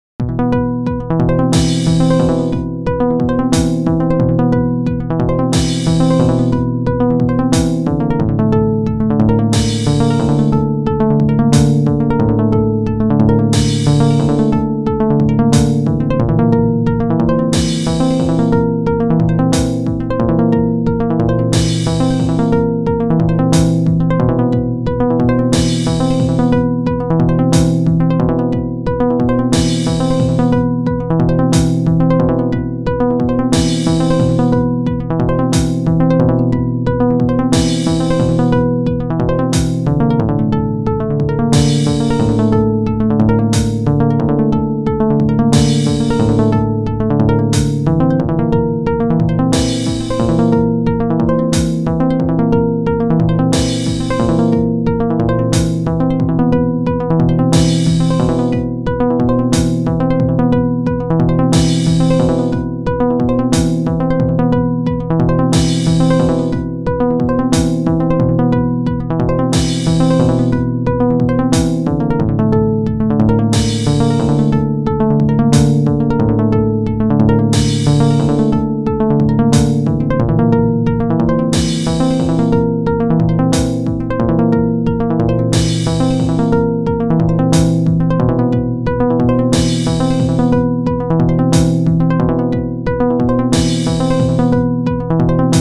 doing my homework in public so it sinks in a little better: this melody is sad due to music theory and i’m going to do my best to explain why; i don’t know much about this stuff and welcome correction
it’s in b locrian, b is the home note, it feels settled in relation to the other notes. i have this dot sequencer hooked up to 2 chorders which feed into a strummer. i was going to have a chain of chorders that switched on and off in order to automate cycling chord progressions rooted in a note that comes in via midi input, but i ended up with two chorders active at the same time because it sounded nice
so anyway this strumming sounds restless and searching for reasons ultimately mysterious to me